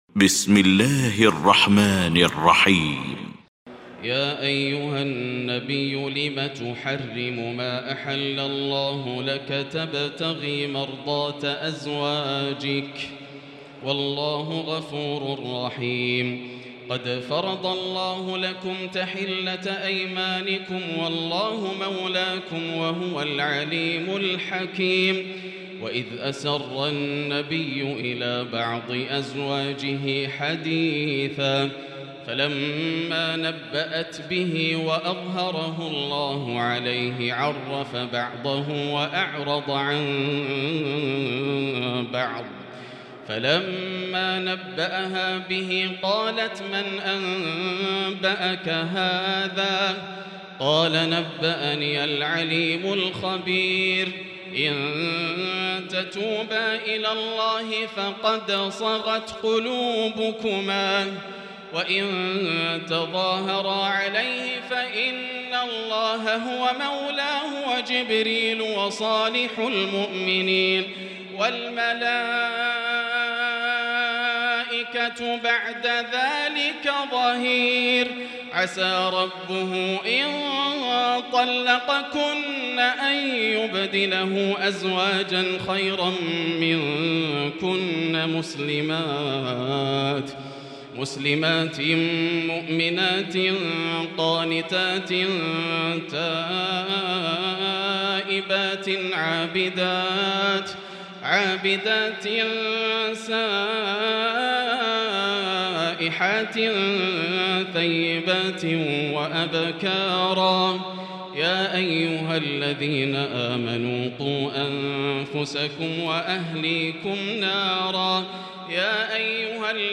المكان: المسجد الحرام الشيخ: فضيلة الشيخ ياسر الدوسري فضيلة الشيخ ياسر الدوسري التحريم